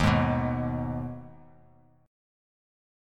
D#sus4#5 chord